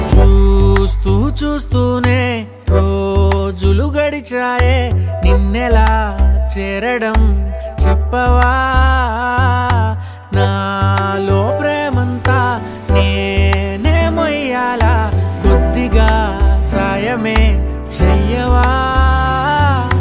Telugu Ringtone